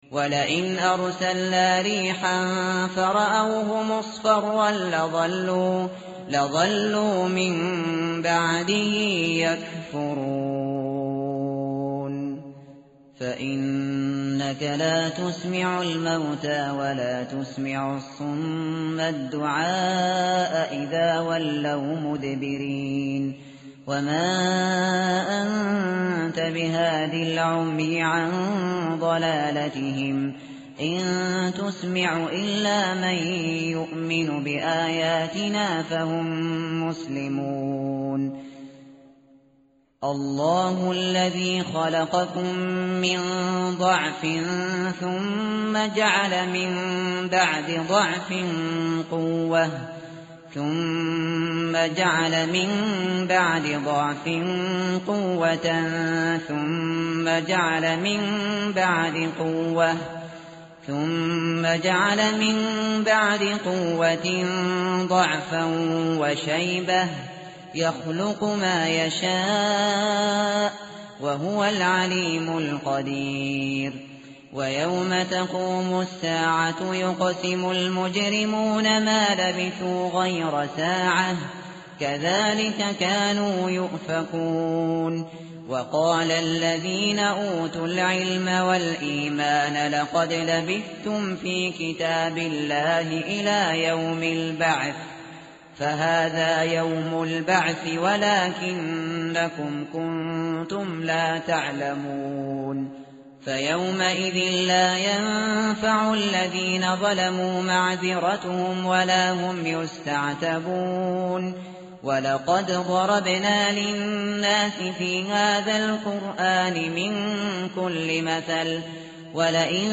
tartil_shateri_page_410.mp3